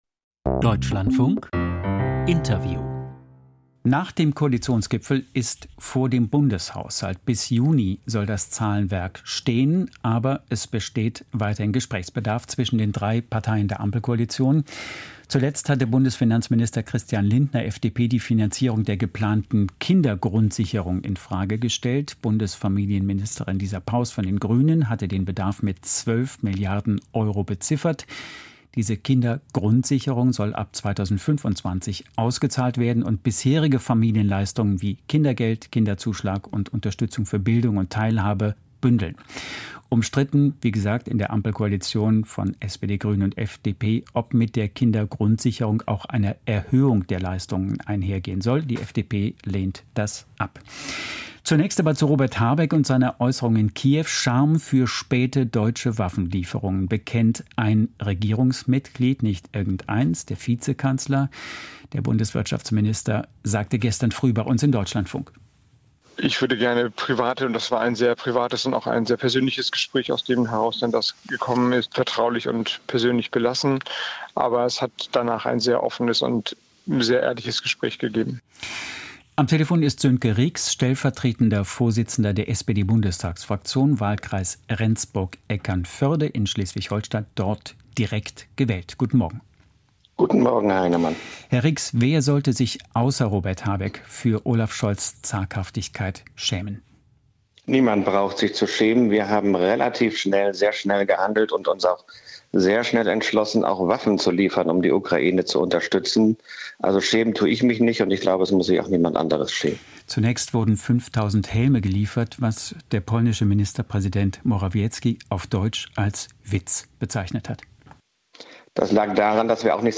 Ampeldisput um Waffenlieferungen, Kindergrundsicherung. Interview Sönke Rix, SPD